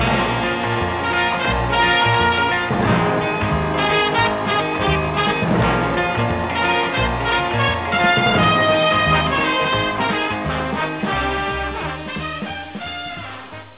Cool theme song, though.)